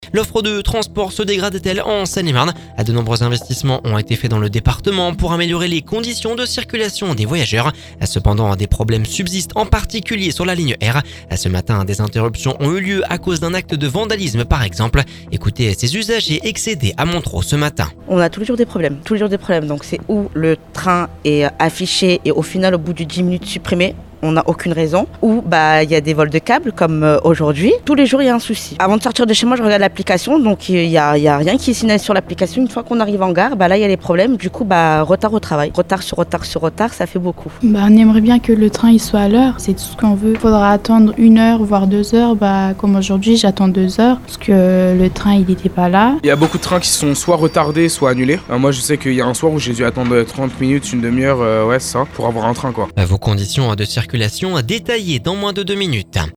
De nombreux investissements ont été fait dans le département pour améliorer les conditions de circulation des voyageurs, cependant des problèmes subsistent en particulier sur la Ligne R.Ce matin des interruptions ont eu lieu à cause d'un acte de vandalisme. Ecoutez ces usagers à Montereau ce matin